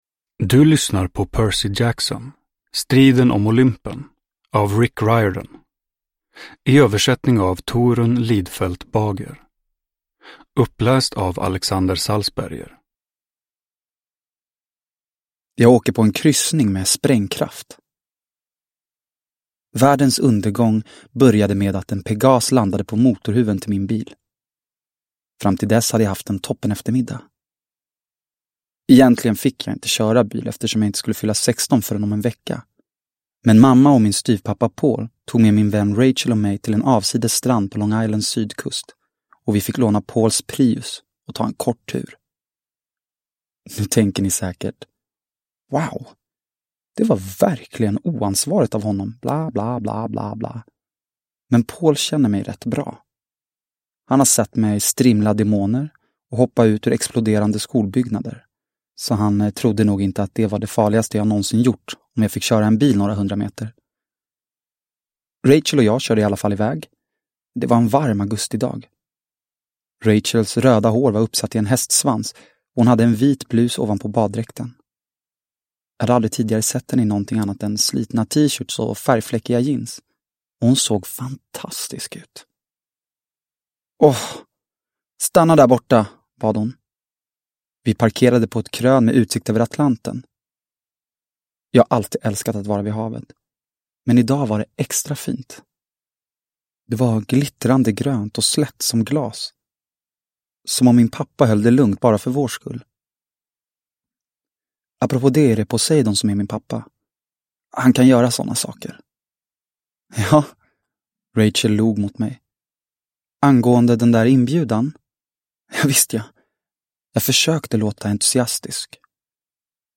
Striden om Olympen – Ljudbok – Laddas ner